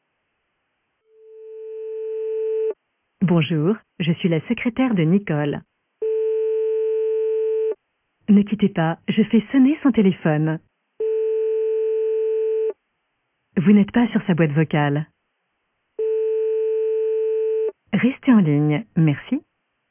- Personnalisez votre tonalité d’attente et faites patienter vos correspondants autrement ! -
Secrétaire En Ligne